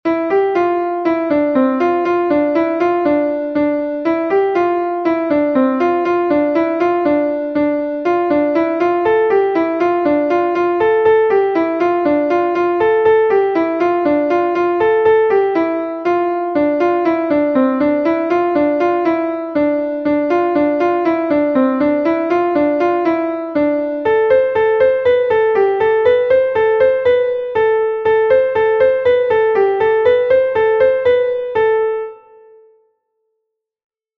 a Plin from Brittany